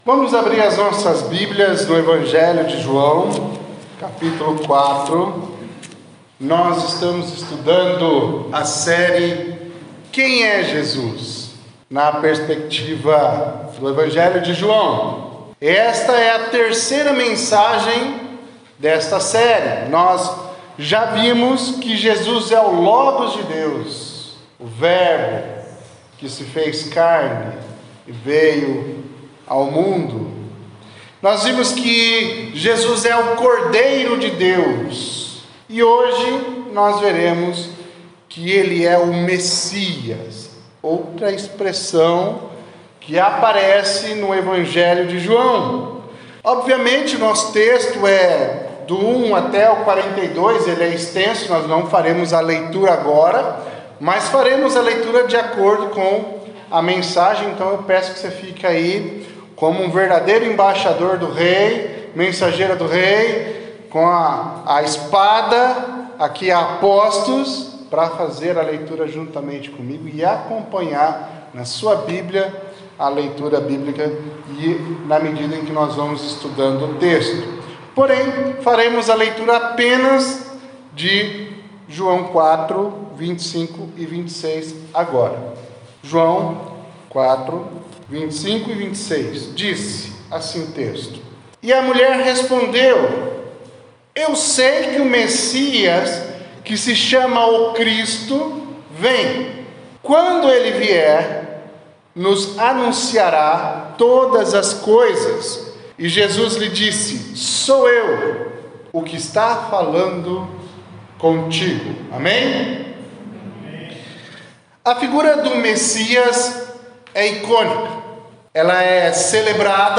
Mensagem do dia